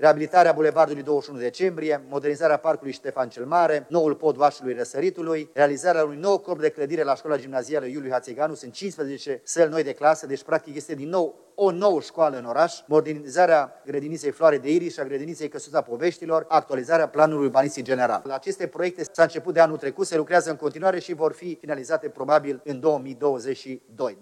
Anul acesta vor continua lucrările și la alte câteva proiecte majore, a mai spus primarul Emil Boc: